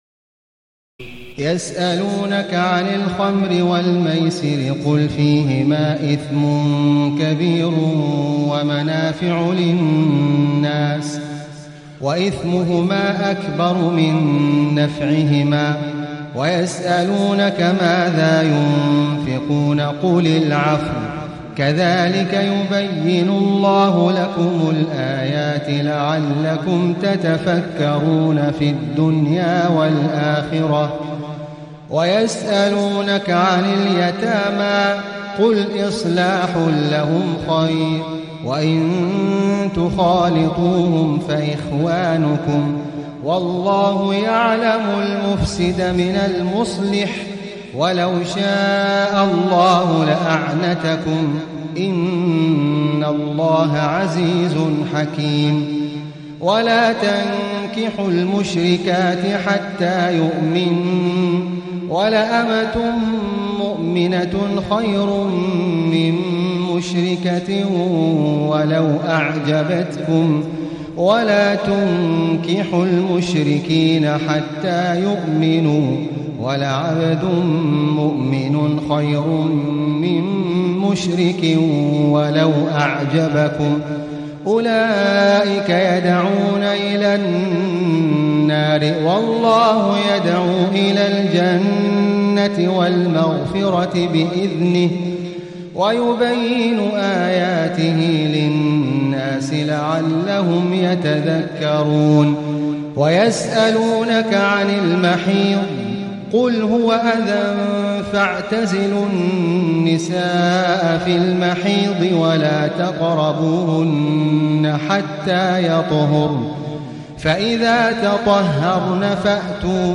تهجد ليلة 22 رمضان 1437هـ من سورة البقرة (219-252) Tahajjud 22 st night Ramadan 1437H from Surah Al-Baqara > تراويح الحرم المكي عام 1437 🕋 > التراويح - تلاوات الحرمين